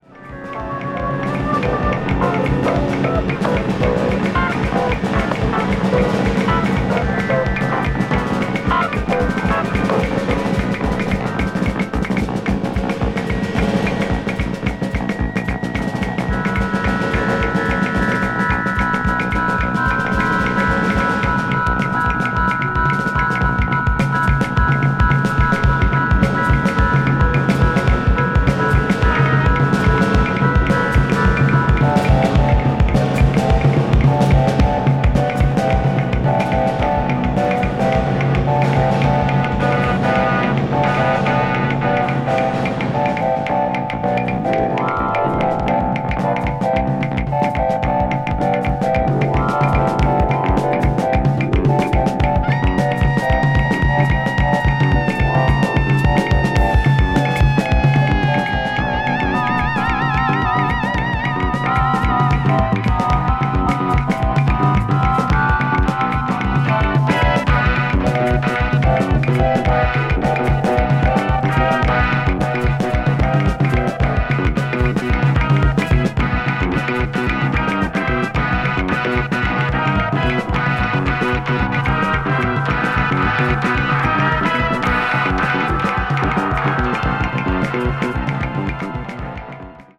A Kraut-jazz-rock group